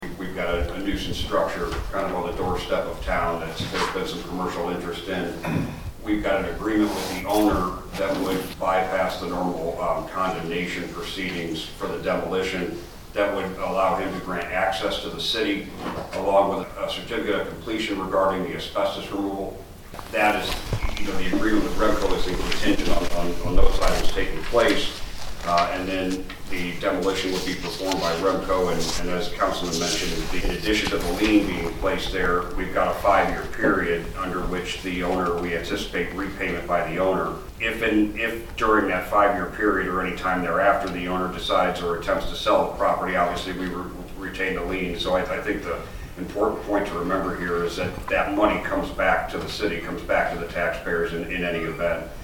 City Administrator JD Kehrman made it clear the city will get its money back over time.